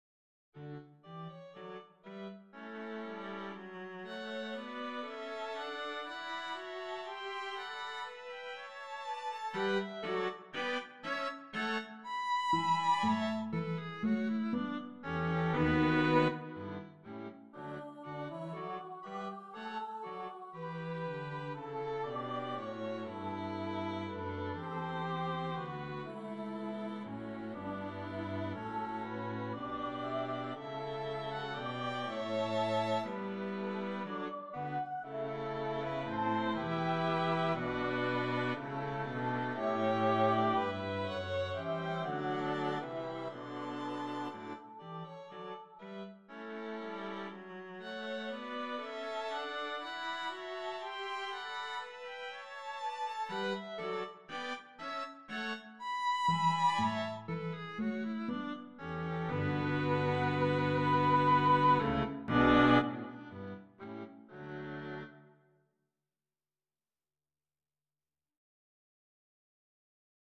for Flute and Strings (with optional Soprano voice)